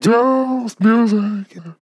Vocal Sample DISCO VIBES
Categories: Vocals Tags: DISCO VIBES, dry, english, FORM, Just, LYRICS, male, music, sample
man-disco-vocal-fills-120BPM-Fm-5.wav